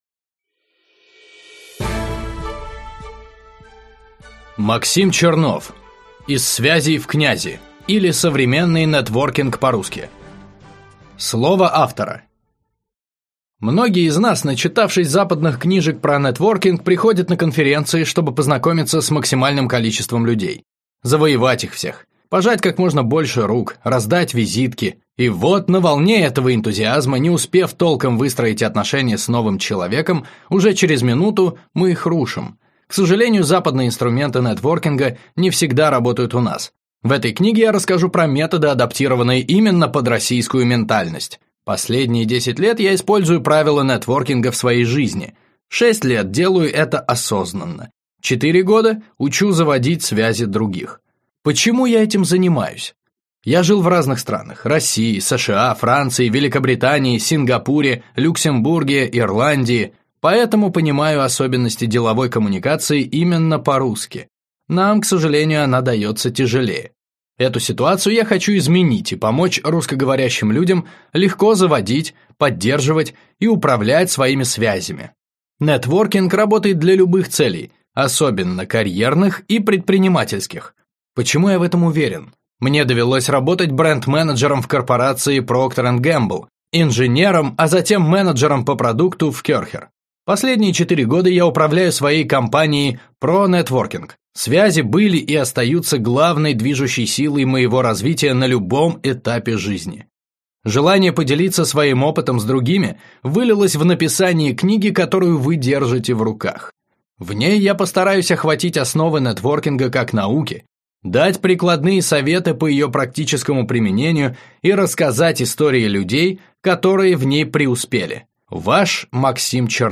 Аудиокнига Из связей – в князи, или Современный нетворкинг по-русски | Библиотека аудиокниг